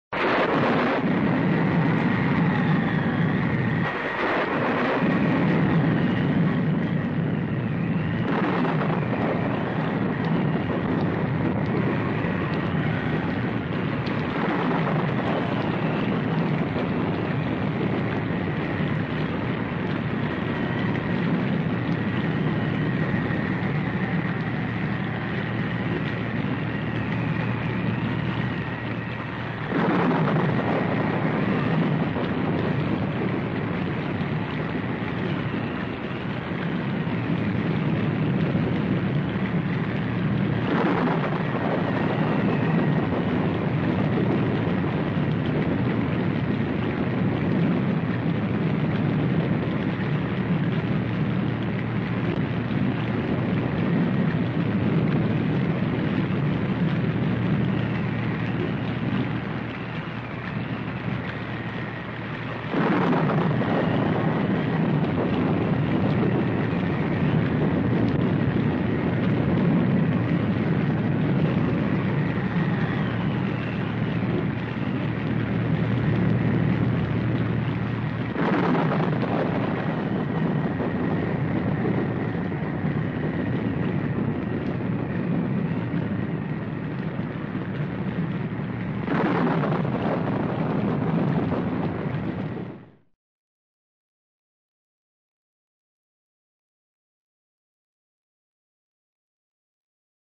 Звук ветра и дождя
Страшная пугающая мистическая ночная гроза — 01мин 40 сек